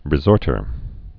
(rĭ-zôrtər)